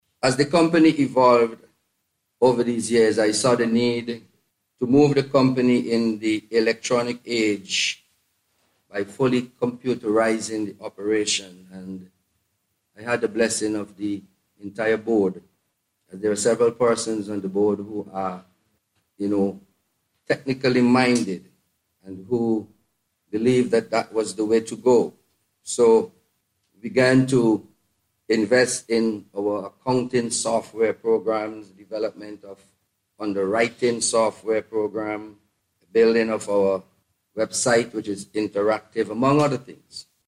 The Sentry Insurance Company hosted a Media Launch this morning as it embarks on activities to celebrate its 50th anniversary.